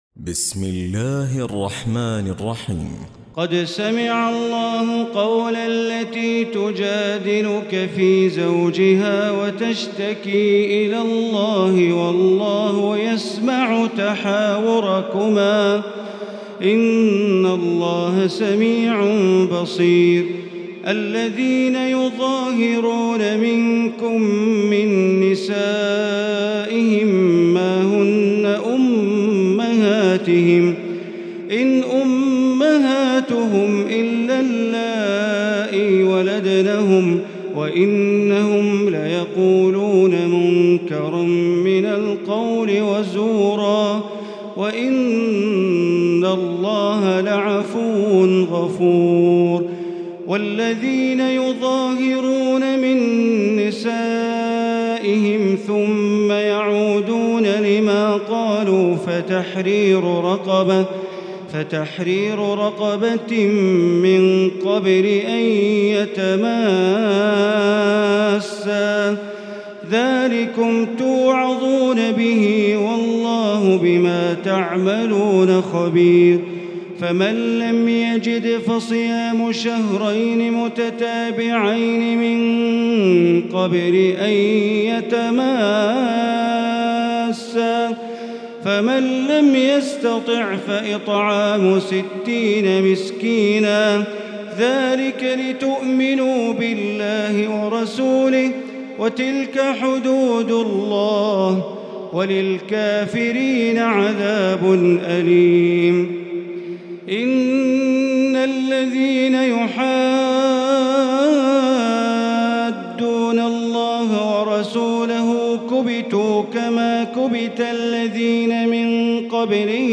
تراويح ليلة 27 رمضان 1438هـ من سورة المجادلة الى الصف Taraweeh 27 st night Ramadan 1438H from Surah Al-Mujaadila to As-Saff > تراويح الحرم المكي عام 1438 🕋 > التراويح - تلاوات الحرمين